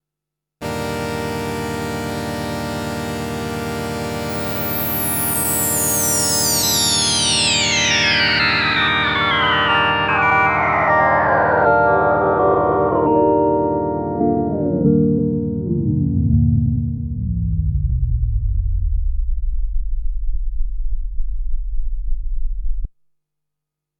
Roland Juno-6 Polyphonic Analog Synthesizer | smem
down the harmonics 00:24
smem_juno-6_down_the_harmonics_0.mp3